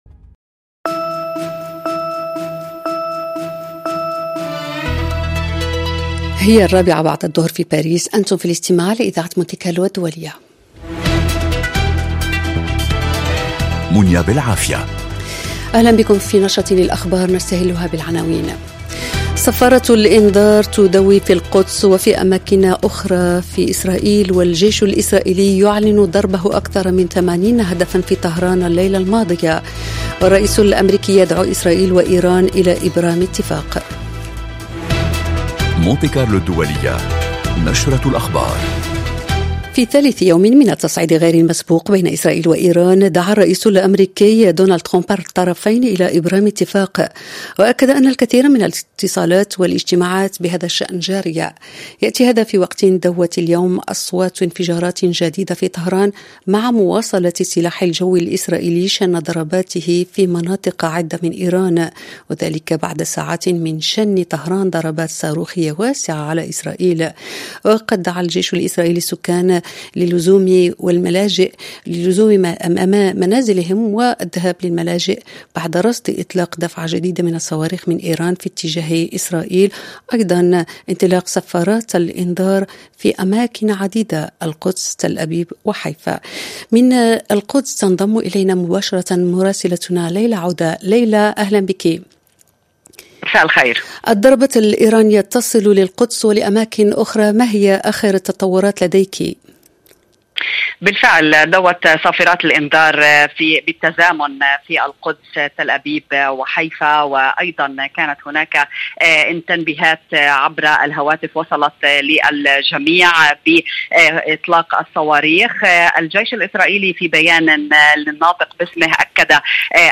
برامج مونت كارلو الدولية من تقديم صحفيين ومذيعين متخصصين وتعتمد في أغلبها على التواصل اليومي مع المستمع من خلال ملفات صحية واجتماعية ذات صلة بالحياة اليومية تهم المرأة والشباب والعائلة، كما أنها تشكل نقطة التقاء الشرق بالغرب والعالم العربي بفرنسا بفضل برامج ثقافية وموسيقية غنية.